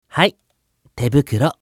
青年ボイス～シチュエーションボイス～